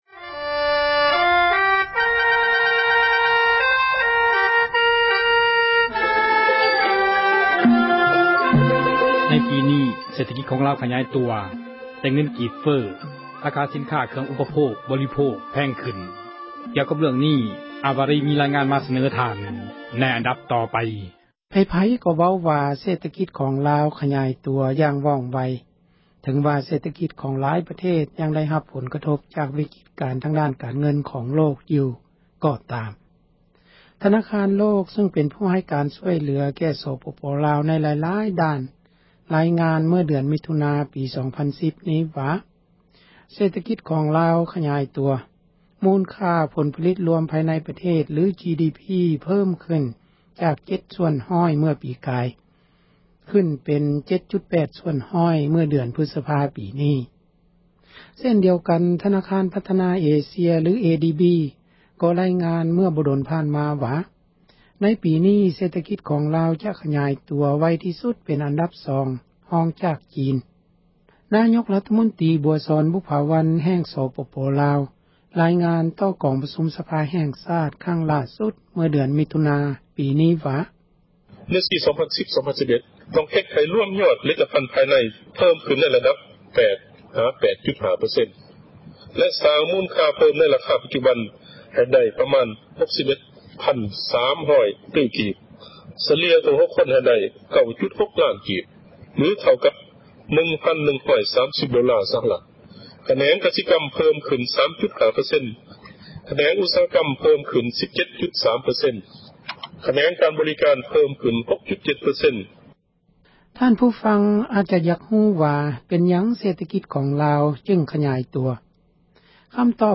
ເສຖກິດລາວຂຍາຍຕົວ ແຕ່ເງິນກີບເຟີ້ – ຂ່າວລາວ ວິທຍຸເອເຊັຽເສຣີ ພາສາລາວ